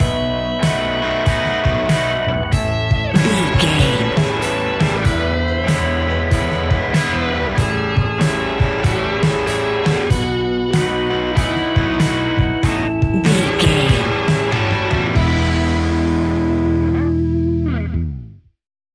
Ionian/Major
hard rock
heavy metal
distortion